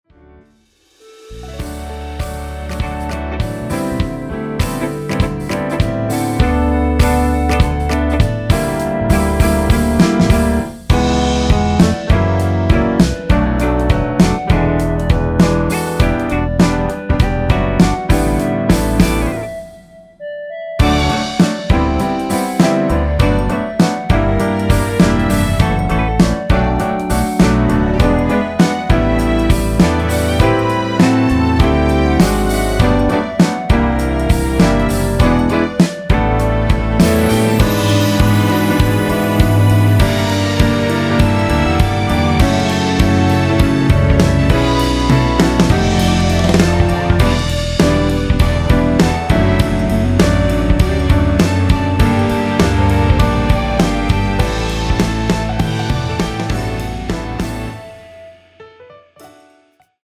원키에서(-1)내린 (1절+후렴)으로 진행되는 멜로디 포함된 MR입니다.
D
노래방에서 노래를 부르실때 노래 부분에 가이드 멜로디가 따라 나와서
앞부분30초, 뒷부분30초씩 편집해서 올려 드리고 있습니다.
중간에 음이 끈어지고 다시 나오는 이유는